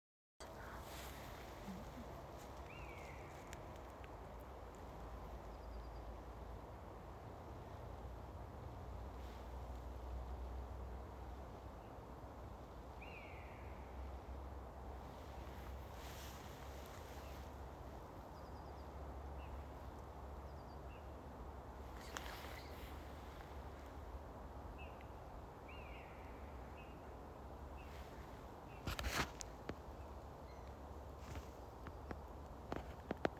Birds -> Birds of prey ->
Common Buzzard, Buteo buteo
StatusVoice, calls heard